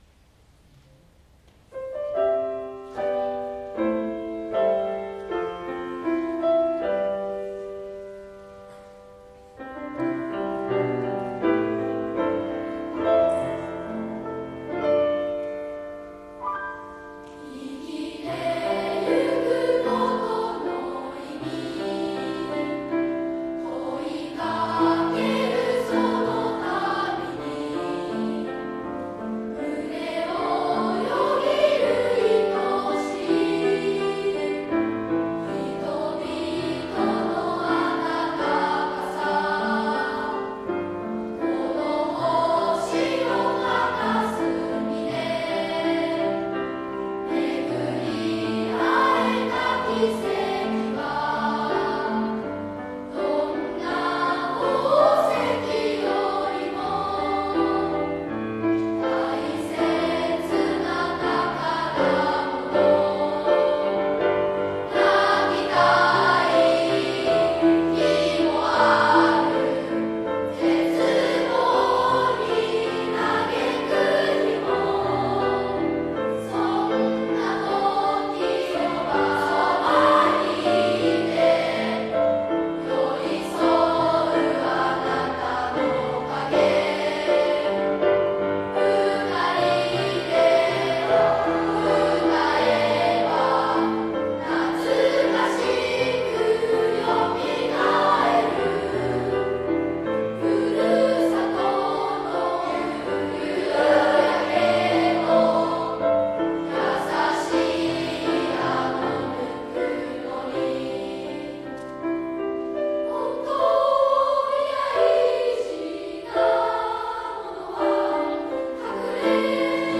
市内小学校の音楽会がFOSTERホールにて開催されました。
合唱「いのちの歌」
自分の命への愛おしさ、家族への感謝の気持ち、この広い地球の中で友達と出会えた不思議、子供たちの純粋な心がきれいなハーモニーとなって、聴く人の胸にぐっと迫ってきました。本当に素敵な歌声でした。